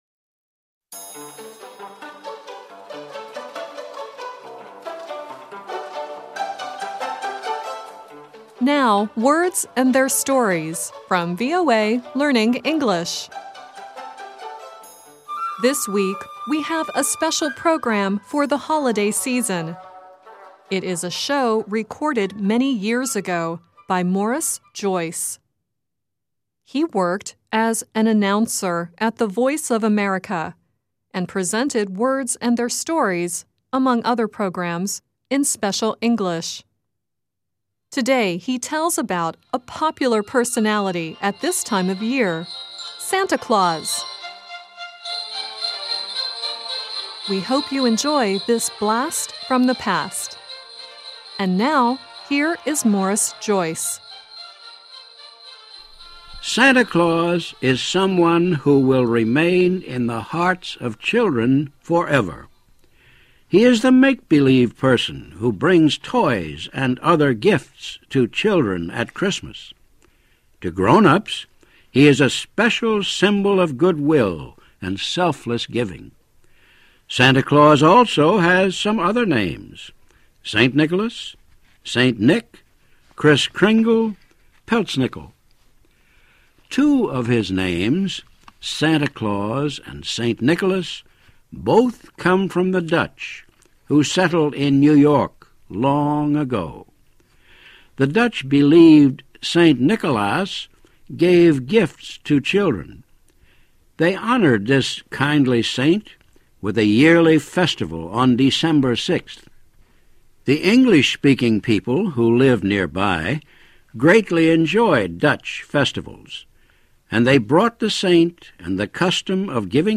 The song at the beginning of the show is the 1949 version of "Sleigh Bells by the Boston Pops Orchestra.